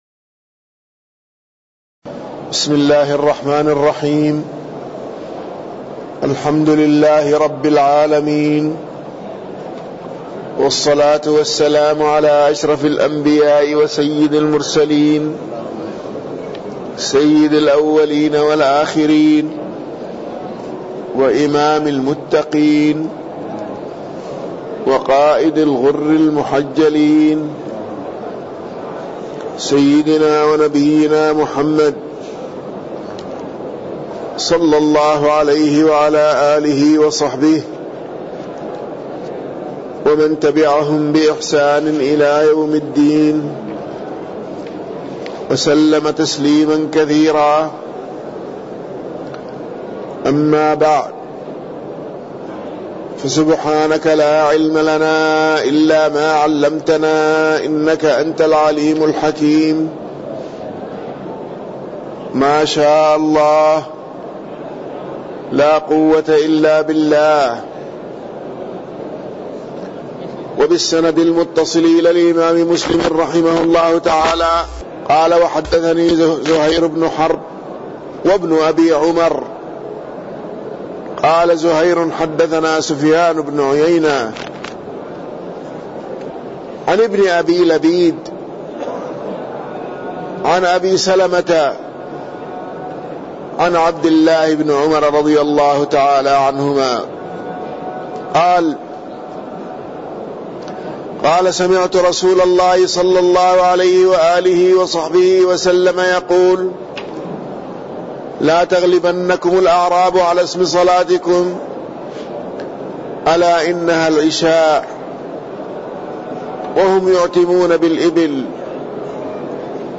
تاريخ النشر ٢٩ محرم ١٤٣٠ هـ المكان: المسجد النبوي الشيخ